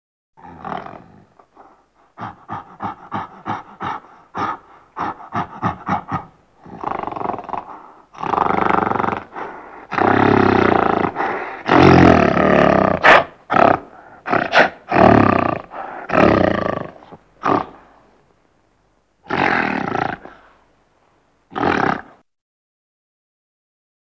Le gorille | Université populaire de la biosphère
il grogne, crie, hurle
gorille.mp3